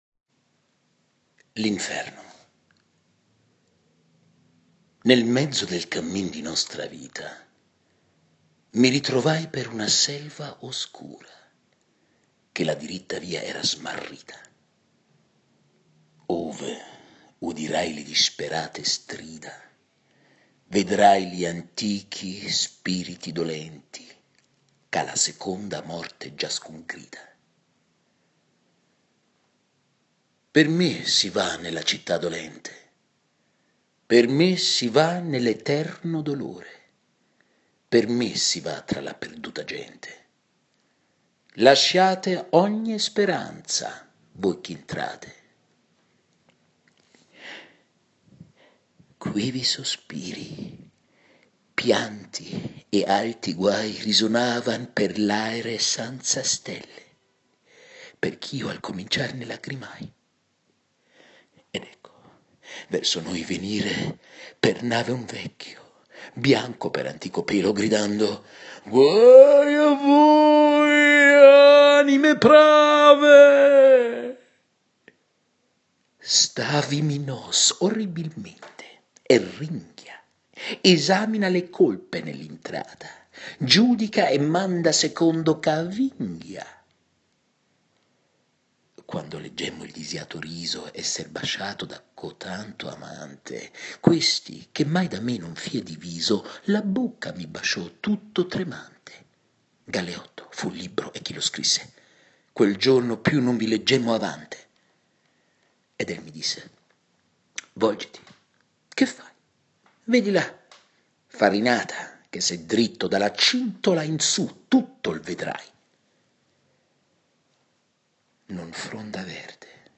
Hörspiel / Fremdsprache - Dante - Inferno - italienisch / geheimnisvoll / mystisch